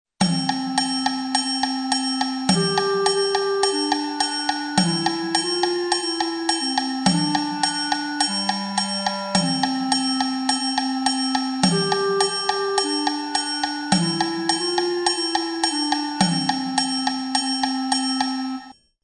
Ascoltiamo e osserviamo : accento metrico ( tamburo ) + accento ritmico di divisione ( triangolo ) + accento ritmico di suddivisione ( legnetti ) ritmico_suddivisione.mp3
ritmico_suddivisione.mp3